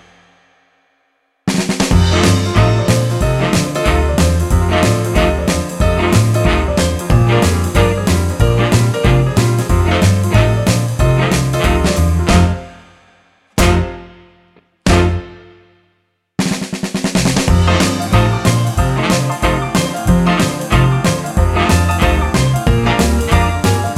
No Piano Rock 'n' Roll 2:28 Buy £1.50